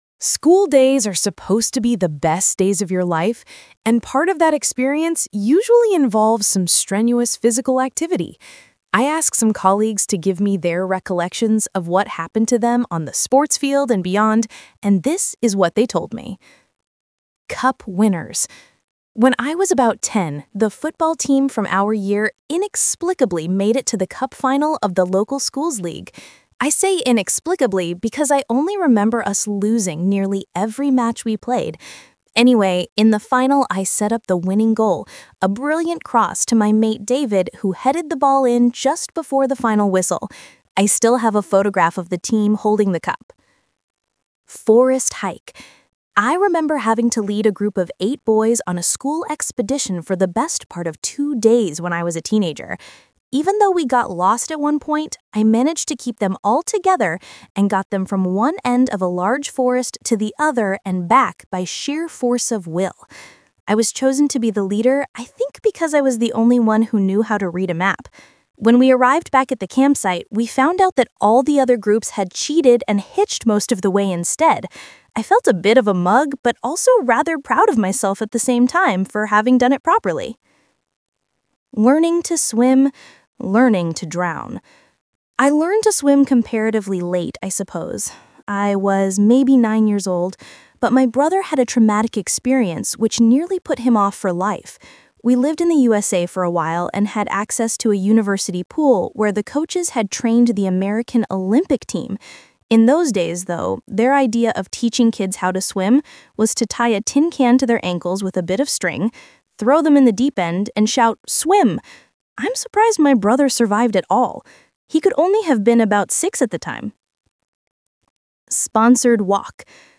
Talk/Lecture 1: You will hear people talking about sports.